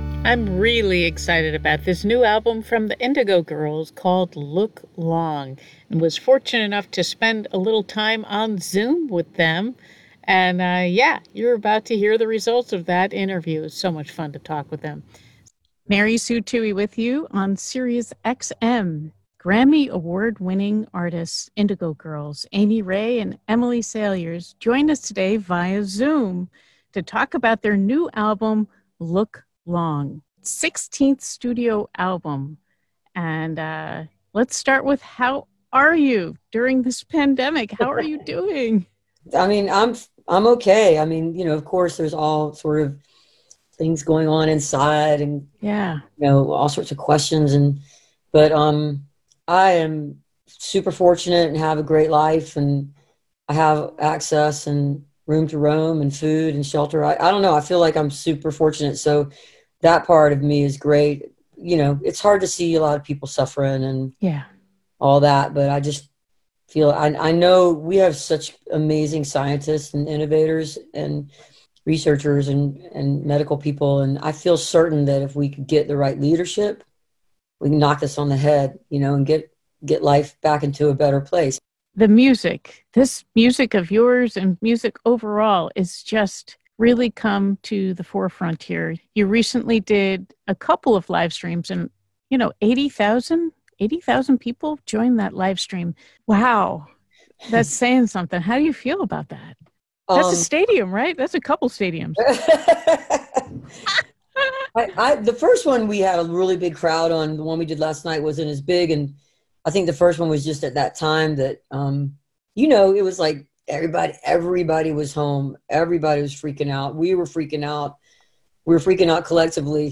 (captured from the web broadcast)
01. interview (3:06)